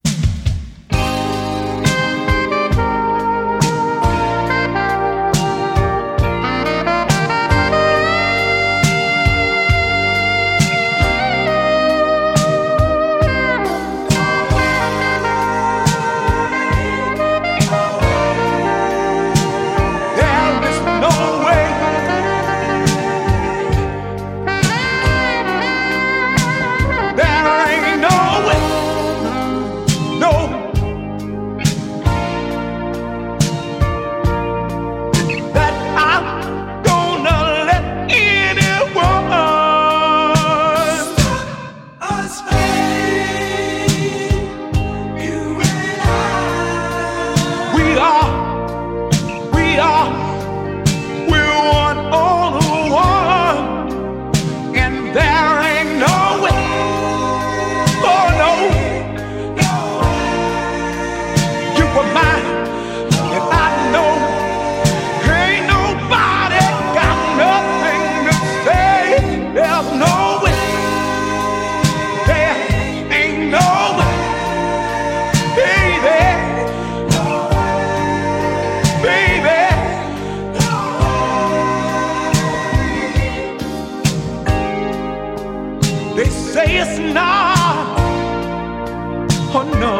ジャンル(スタイル) BOOGIE / FUNK / SOUL